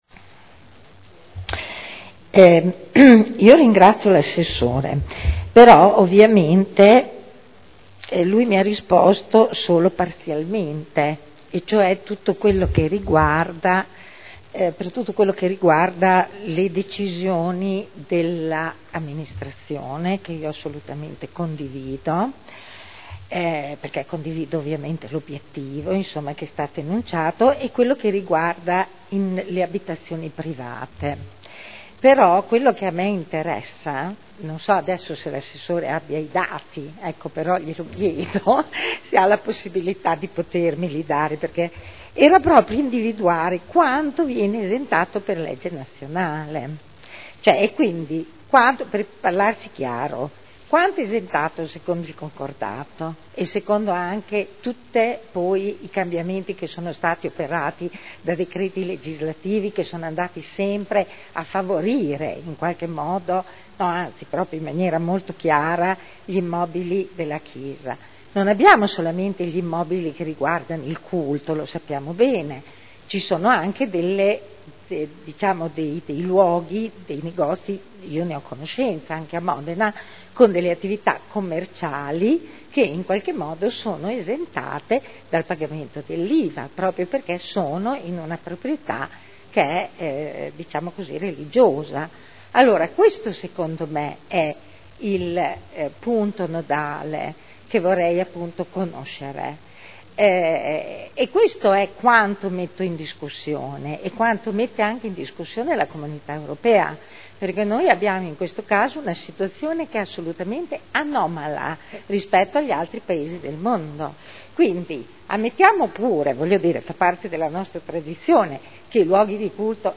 Seduta del 28/04/2011. Replica a risposta dell'Assessore Colombo su Interrogazione della consigliera Rossi E. (IdV) avente per oggetto: “Esenzione ICI” (presentata il 28 febbraio 2011 – in trattazione il 28.4.2011) (Assessore Alvaro Vito G. Colombo)